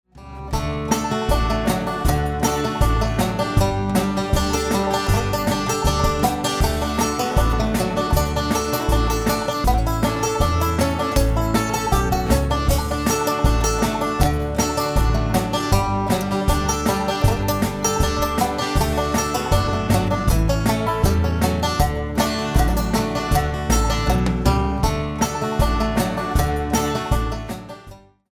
Song is demonstrated and then taught totally be ear.